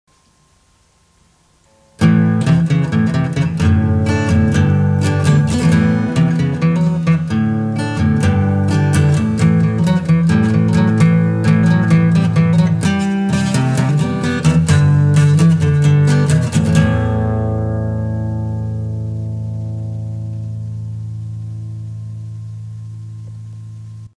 ロック・バッキング
後半、押弦がむずくてびびり入りまくりっす。 ま、ロックの荒々しさが出て、これはこれでいい味でてるかな。
rock_backing.mp3